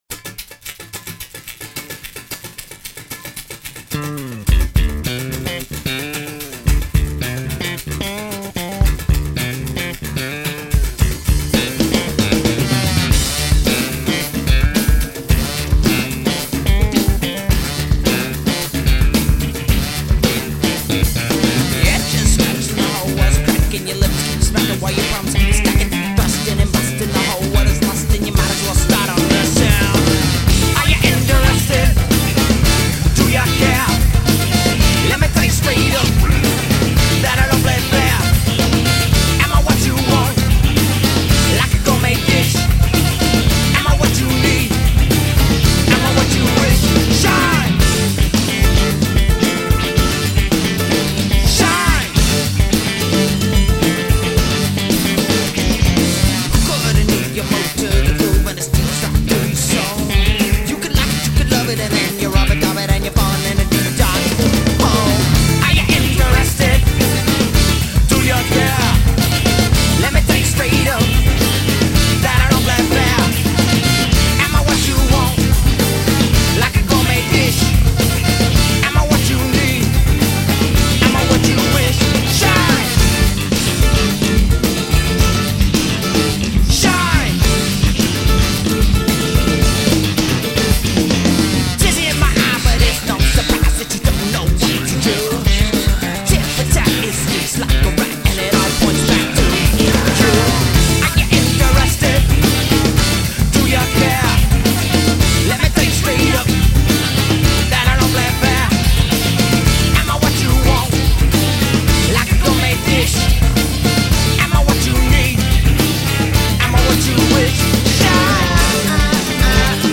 guitar, vocals, percussion, flute, trombone
guitar, bass, vocals, percussion
drums, percussion